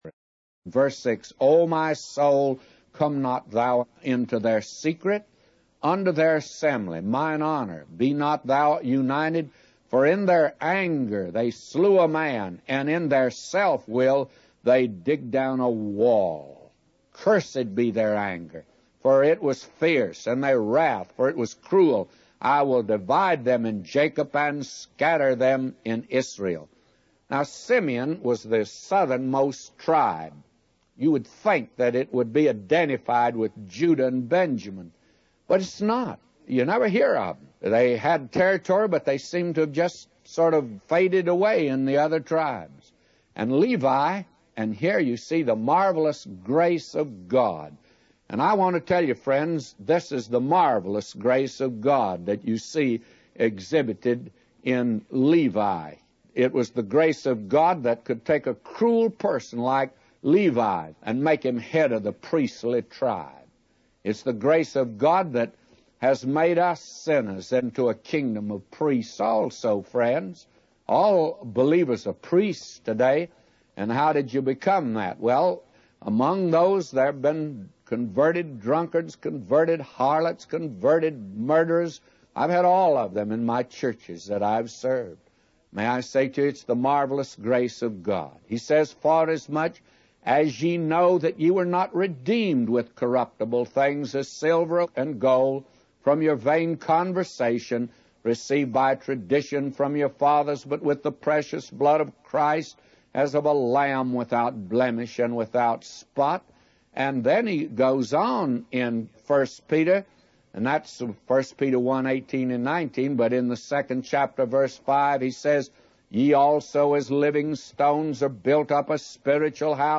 A Commentary By J Vernon MCgee For Genesis 49:6-999